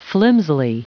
Prononciation du mot flimsily en anglais (fichier audio)
Prononciation du mot : flimsily